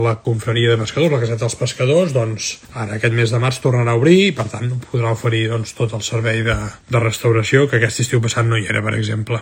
El projecte del govern vol reforçar el caràcter familiar i obert del passeig, i alhora recuperar la memòria marinera de la ciutat amb referències a les antigues drassanes que ocupaven l’actual edifici del restaurant La Llar dels Pescadors, al que s’hi està fent un rentat de cara. L’alcalde en confirmava la reobertura després d’un any tancat.